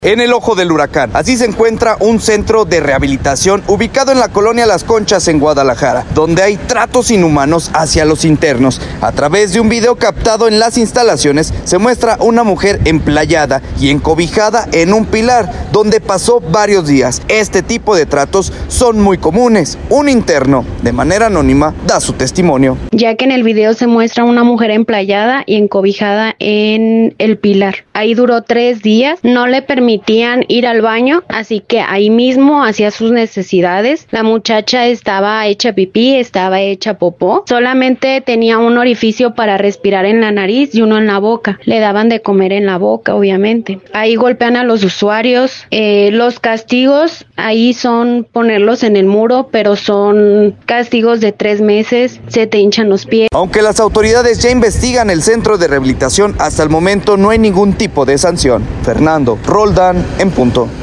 Un interno de manera anónima da su testimonio